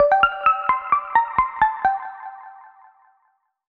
Mellow Hint 2.wav